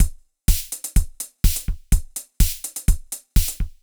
IBI Beat - Mix 5.wav